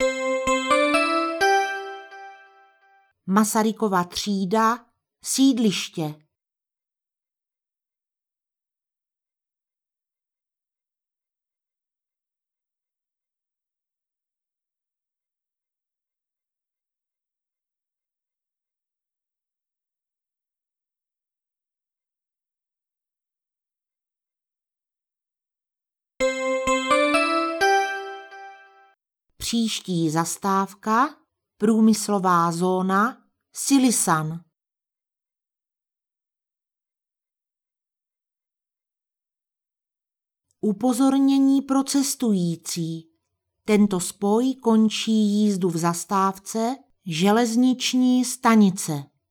Dnes nepřidáme žádné obrázky, ale zato pro vás máme ukázku hlášení zastávek. Jedná se o jednu souvislou nahrávku, součástí jsou proto i mezery, které slouží jak o ohlášení aktuální zastávky, tak i ohlášení zastávky příští pro nově nastoupivší cestující.
Kdyby nemluvila tak dlouze a trochu plynuleji a přirozeněji, tak by to bylo ještě lepší. :)
Taky bych byl radši, kdyby mluvila trochu rychleji a změnil bych ten gong.
Zní to hrozně, jako stroj...má mluvit jako člověk, přirozeně... ;)
hlasatelka.wav